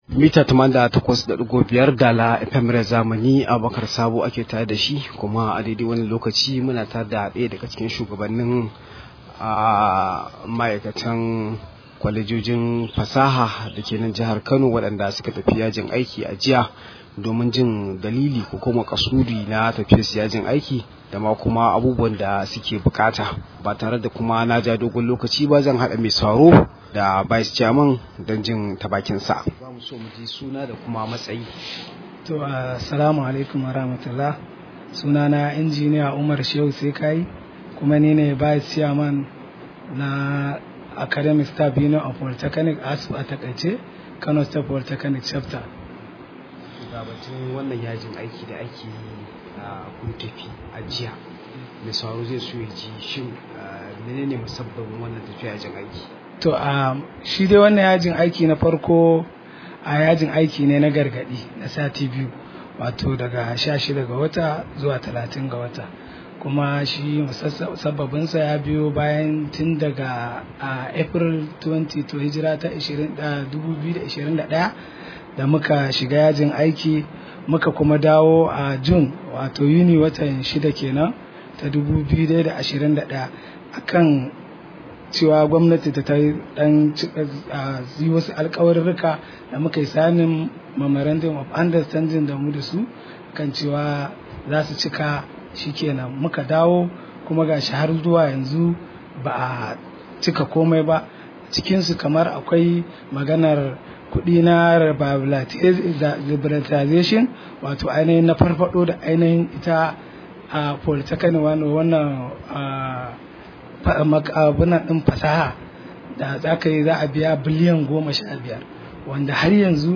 Rahoto: Idan ba mu daidaita da gwamnati ba za mu bayyana matakin mu na gaba – ASUP